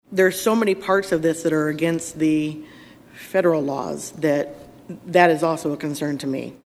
Senator Molly Donahue, a Democrat from Cedar Rapids, says the bill would put kids in harm’s way.